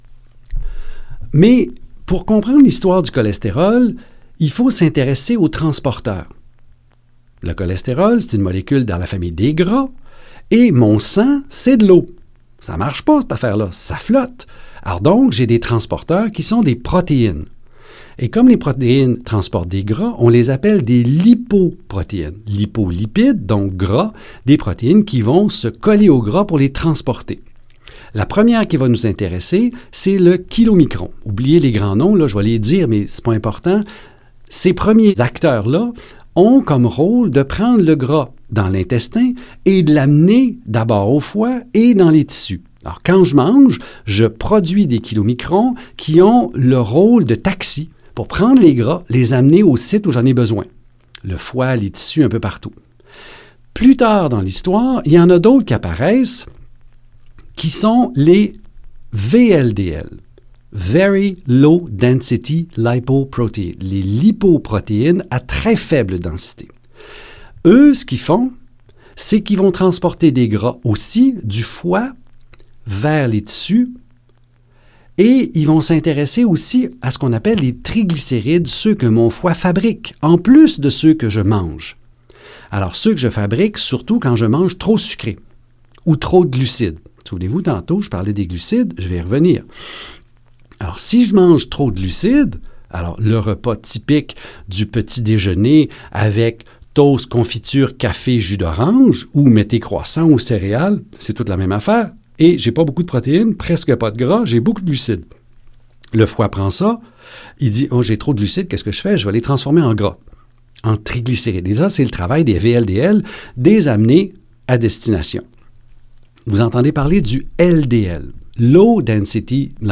Dans ce livre audio, nous verrons d’où vient cette peur du « mauvais cholestérol » et ce qu’on devrait faire à son sujet.